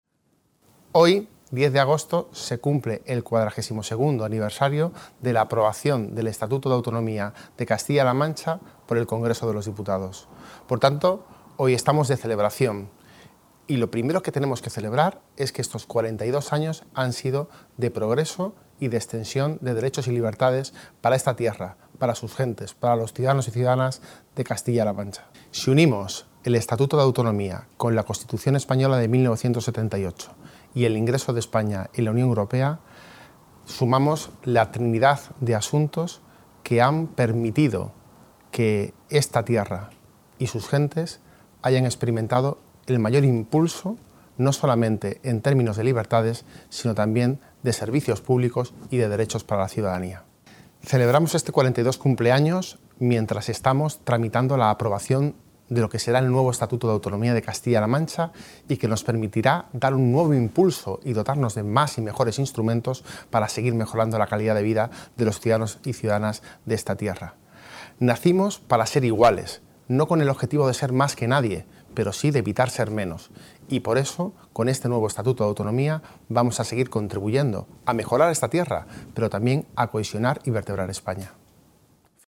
En un vídeo institucional lanzado por las Cortes regionales con motivo, hoy 10 de agosto, del 42º aniversario de la aprobación en las Cortes Generales del Estatuto de Autonomía, Bellido ha recordado que las más de cuatro décadas de proceso autonómico lo han sido de “progresos y de extensión de derechos y libertades para esta tierra y su ciudadanía”, en un recorrido en el que el nacimiento de la comunidad supuso, junto con la Constitución Española y el ingreso en la Unión Europea, “la trinidad de asuntos que han permitido a esta tierra experimentar el mayor impulso de su historia”.